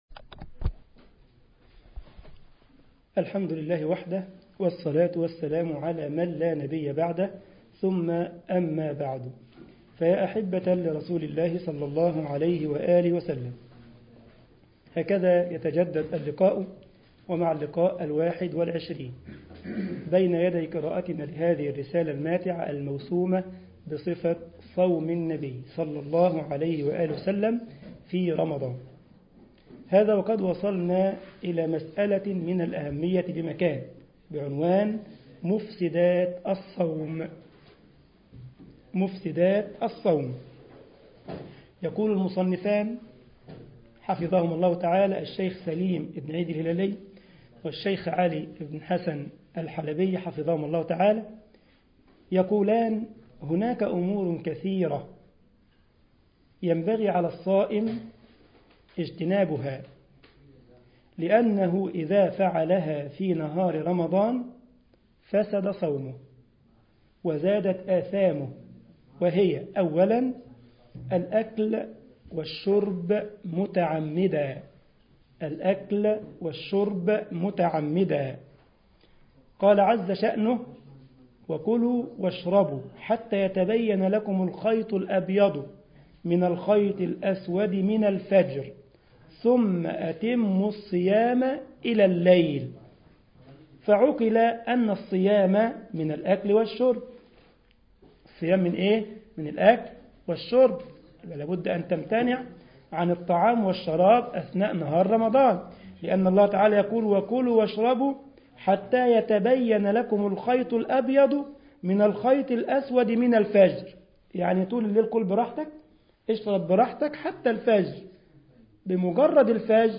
مسجد الجمعية الاسلامية بالسارلند المانيا